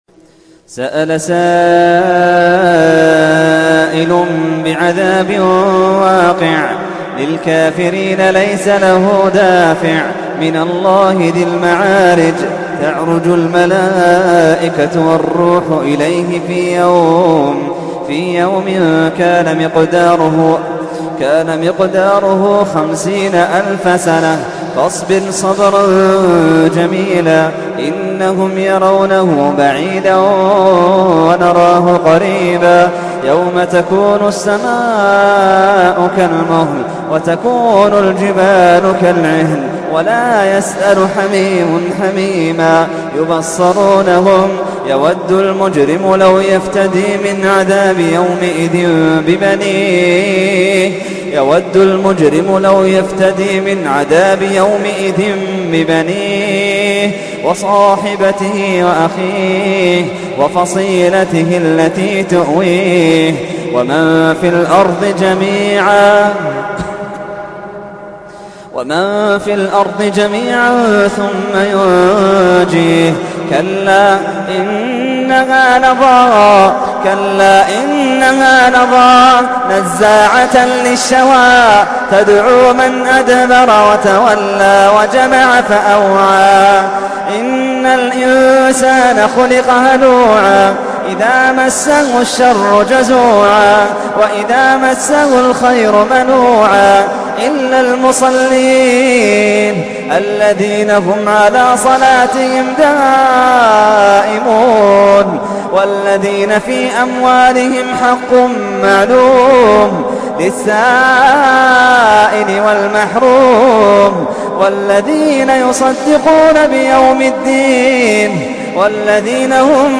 تحميل : 70. سورة المعارج / القارئ محمد اللحيدان / القرآن الكريم / موقع يا حسين